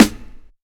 Quick Snare.wav